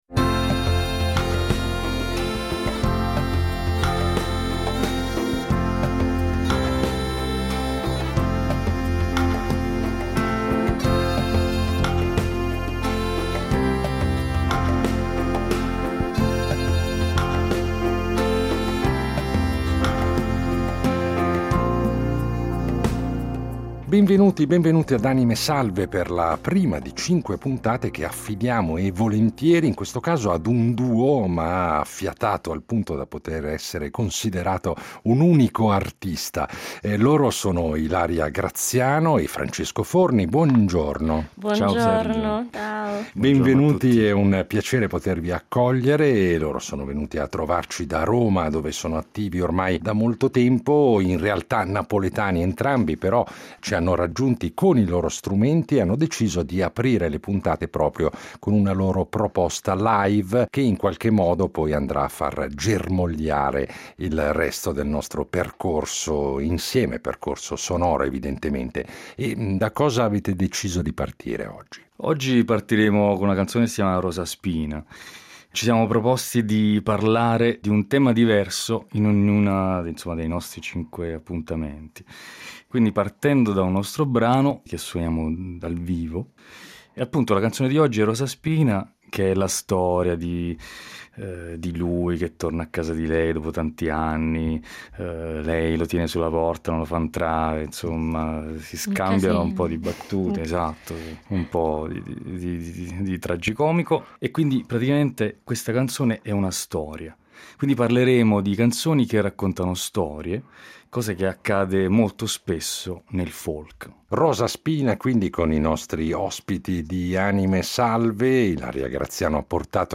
due voci, una chitarra, un ukulele e tante idee
Per nostra fortuna hanno portato i loro strumenti, quindi ce ne daranno un saggio esclusivo.